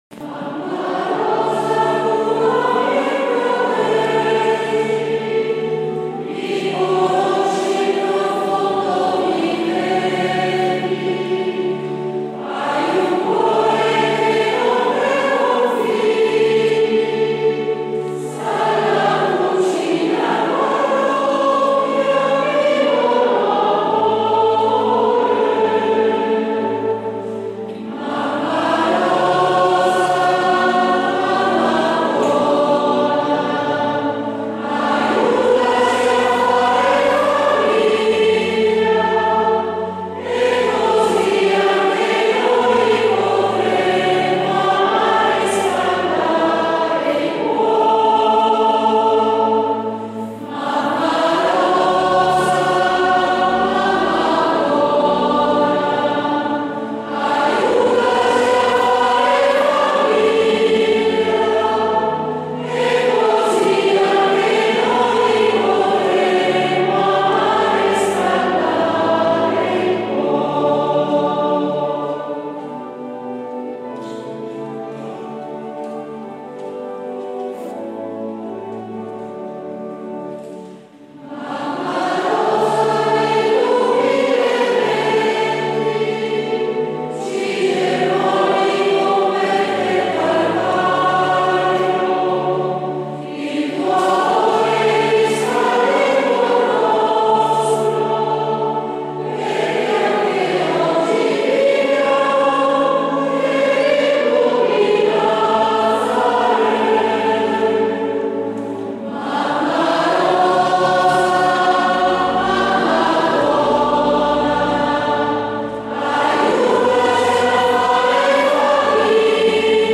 Inno